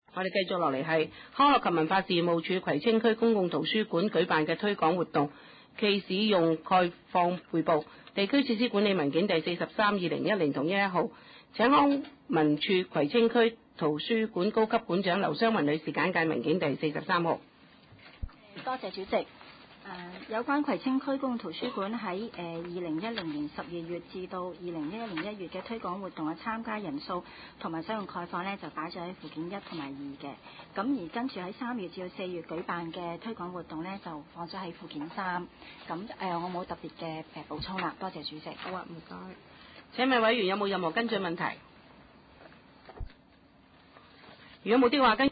第六次會議(一零/一一)
葵青民政事務處會議室